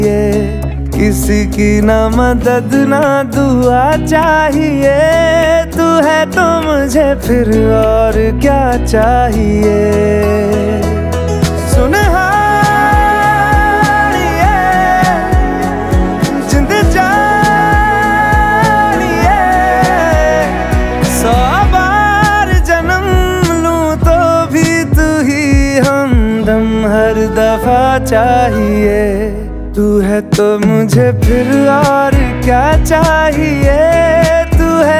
# Bollywood